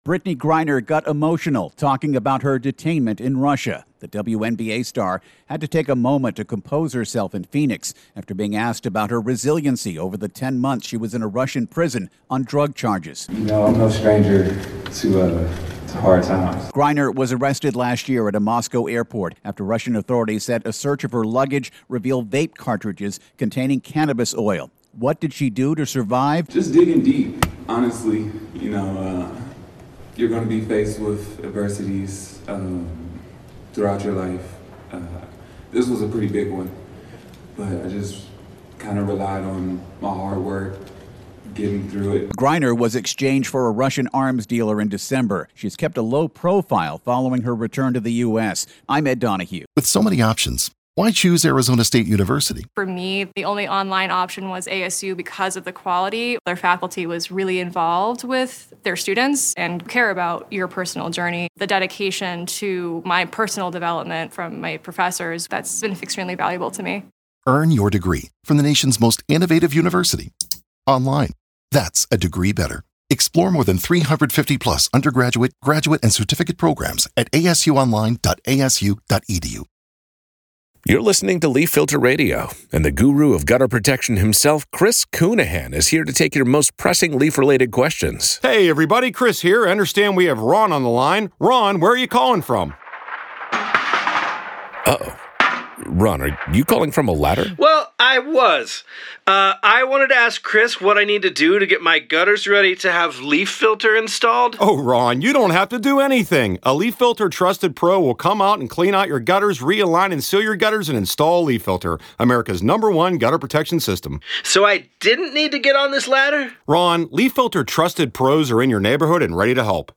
Brittney Griner gets emotional discussing Russian detainment